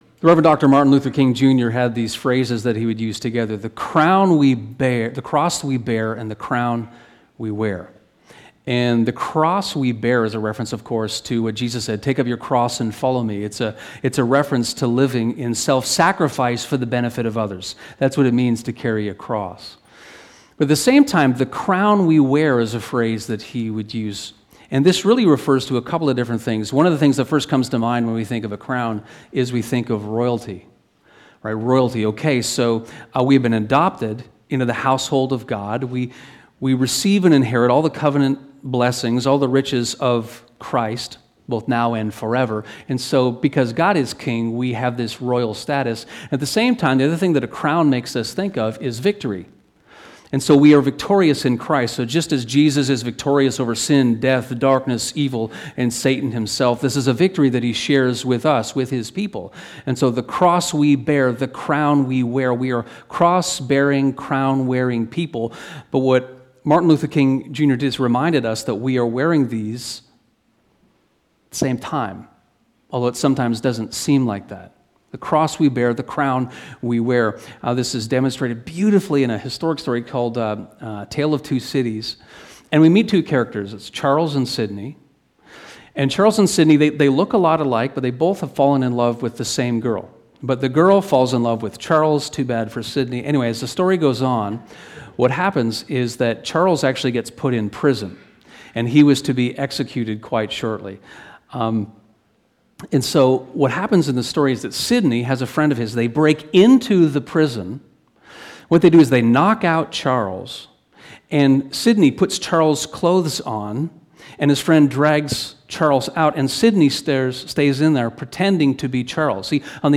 Yes to Light, No to Darkness (Sermon)